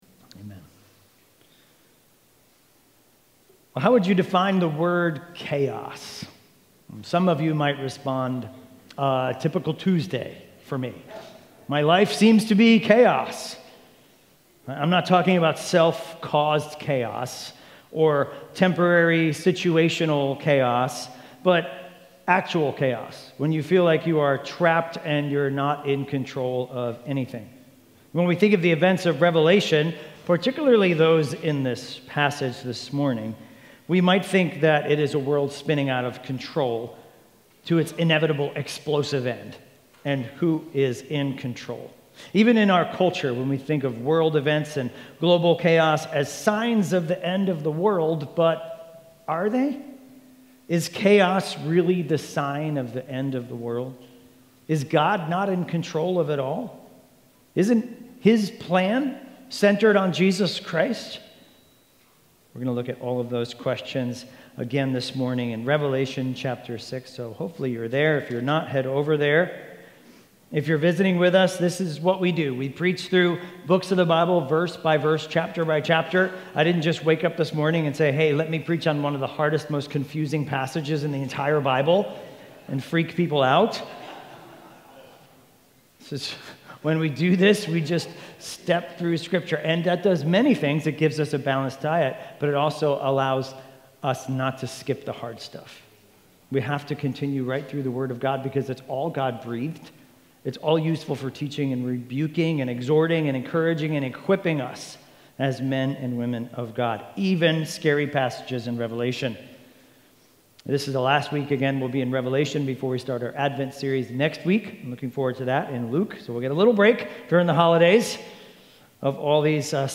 Expositional preaching series through the book of Revelation.